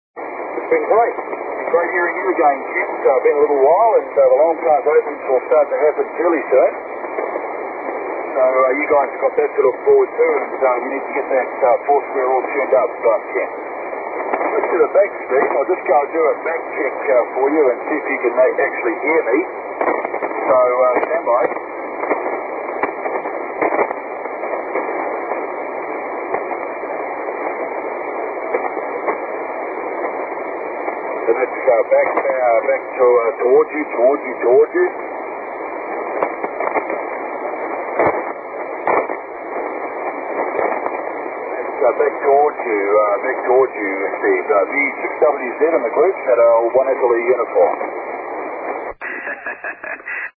If possible use headphones to improve the copy....these recordings are mostly of difficult, lowband QSO's which are often just at the edge of readability.....some signals are very light, but  mostly all Q-5.....afterall...nobody works 80 or 160 without headphones...do they?